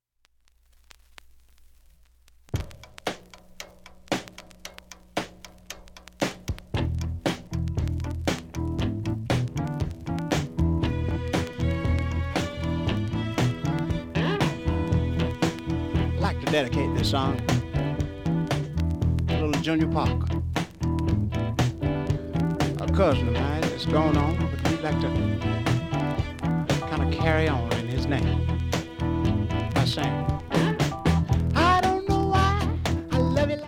４０秒の間に周回プツ出ますがかすかで、
聴き取り出来るか不安なレベルです。